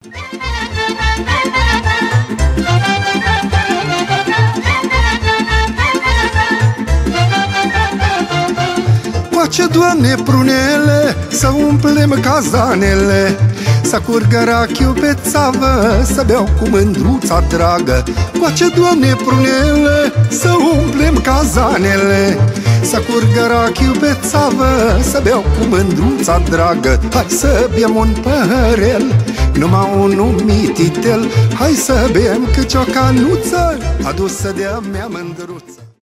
танцевальные , зарубежные , фолк , румынские